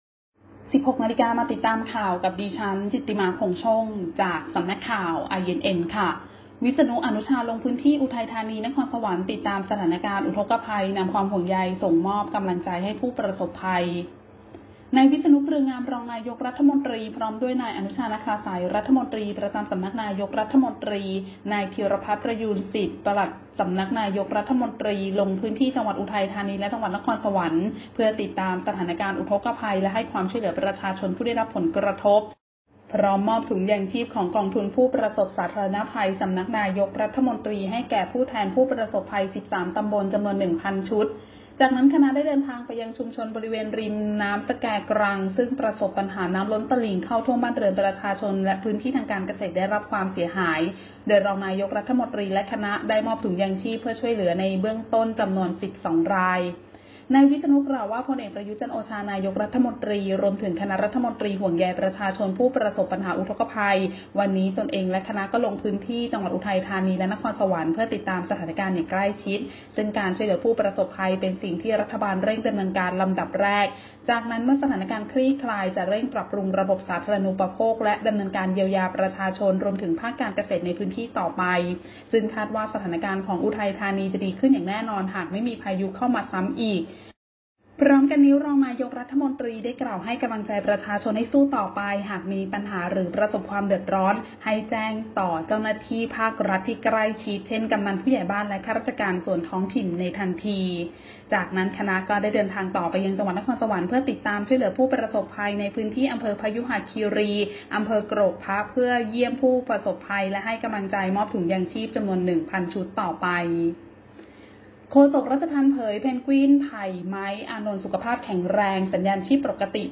คลิปข่าวต้นชั่วโมง
ข่าวต้นชั่วโมง 16.00 น.